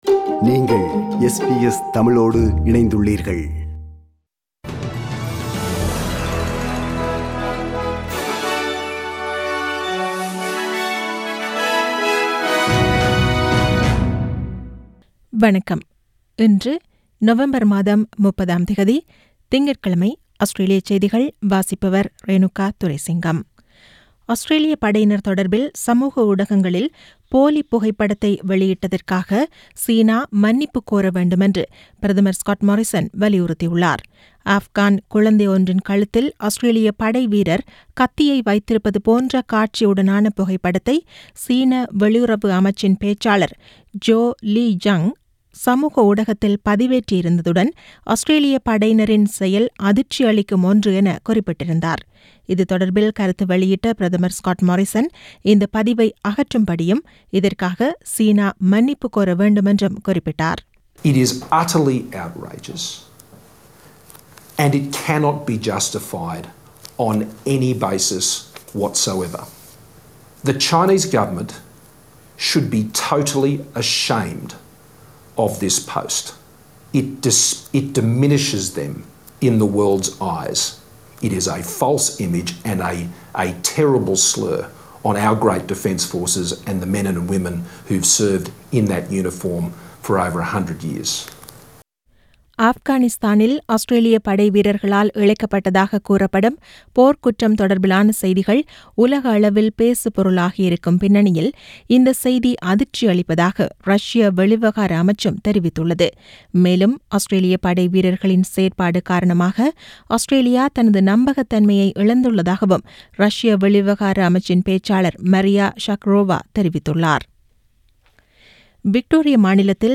Australian news bulletin for Monday 30 November 2020.